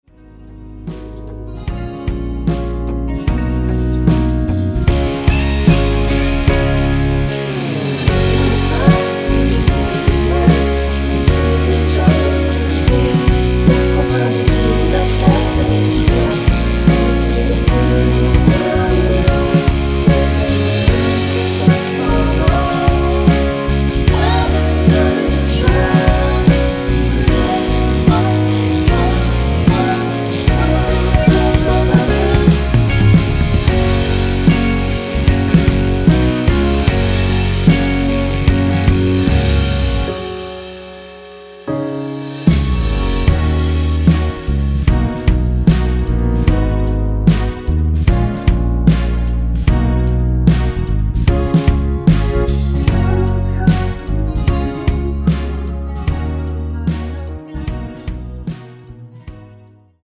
원키 코러스 포함된 MR입니다.
Gb
앞부분30초, 뒷부분30초씩 편집해서 올려 드리고 있습니다.
중간에 음이 끈어지고 다시 나오는 이유는